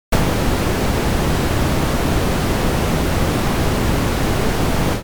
SE（砂嵐 ノイズ）
砂嵐。ゴーッ。